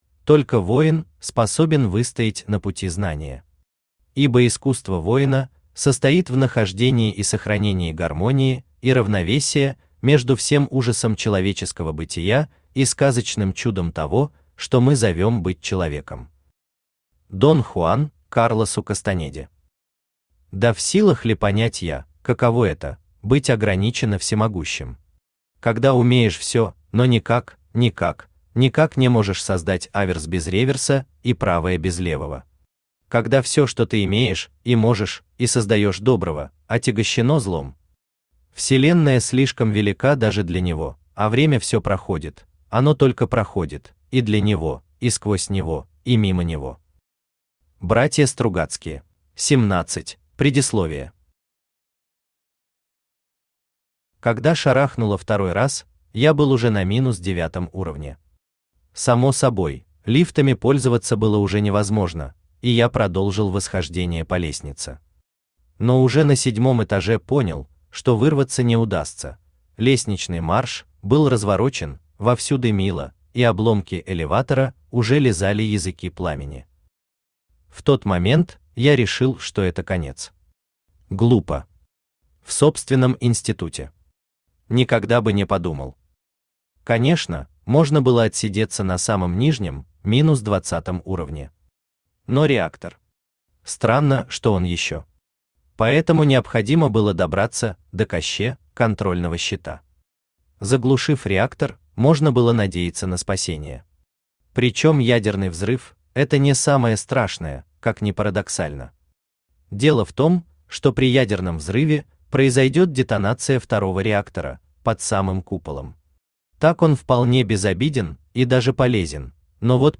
Аудиокнига Шаги по воде | Библиотека аудиокниг
Aудиокнига Шаги по воде Автор Василий Ворон Читает аудиокнигу Авточтец ЛитРес.